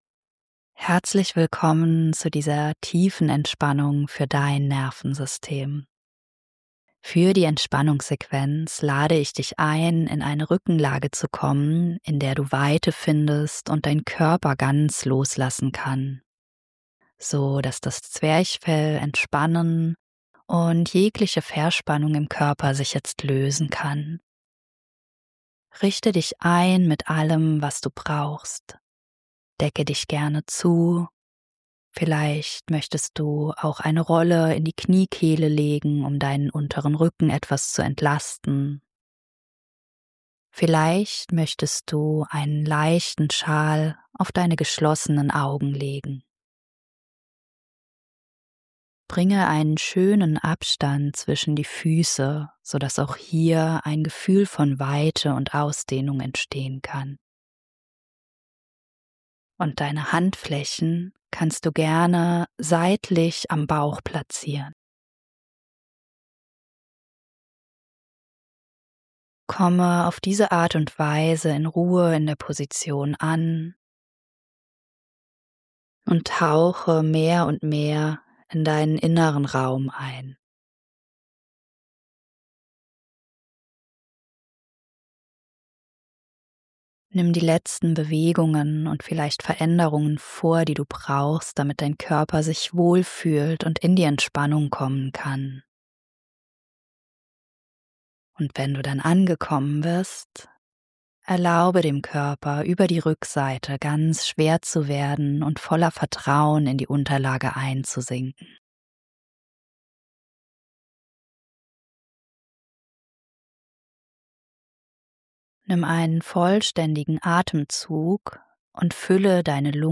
Tiefenentspannung für dein Nervensystem I Atem und Körper beruhigen (ohne Musik)